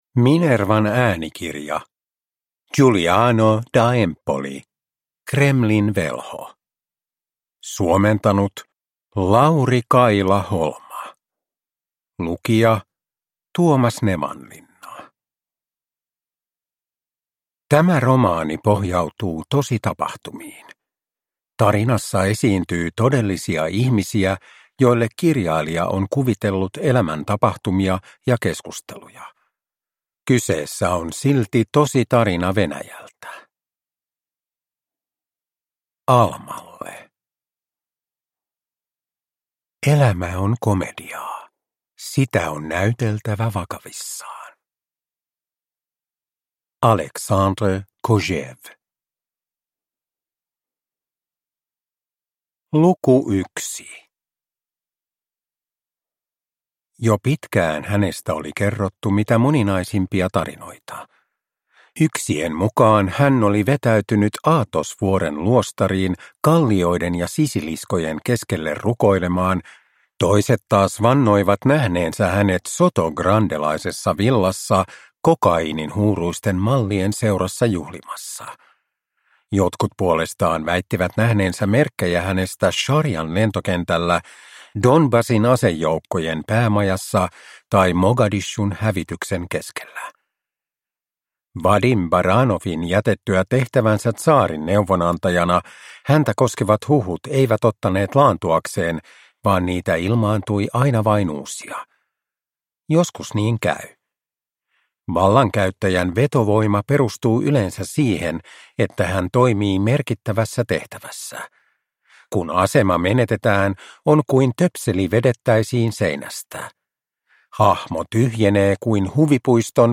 Uppläsare: Tuomas Nevanlinna